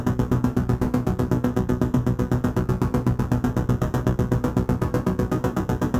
Index of /musicradar/dystopian-drone-samples/Tempo Loops/120bpm
DD_TempoDroneC_120-B.wav